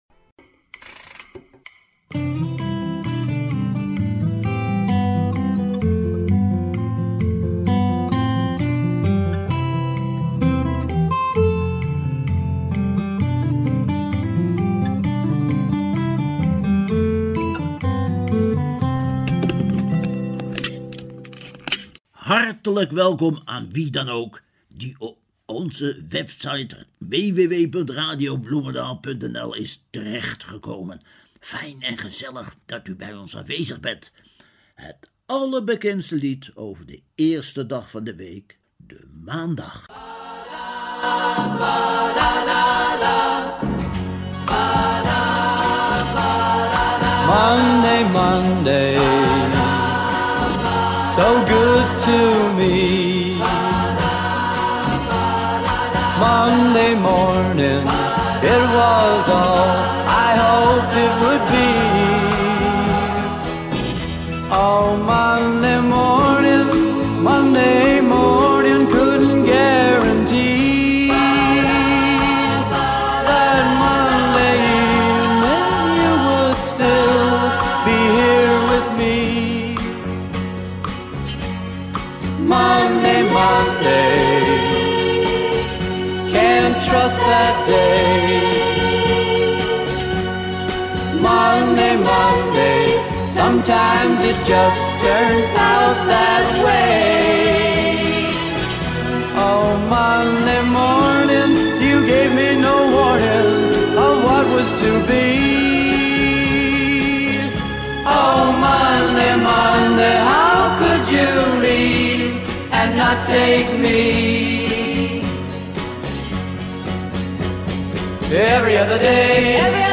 MONDAY, een opname van een Amerikaans zangkwartet voor de helft bestaand uit zangeressen uit de 60-er jaren.
Alleen cryptisch zou je van een taal kunnen spreken: instrumentaal en van Nederlandse bodem.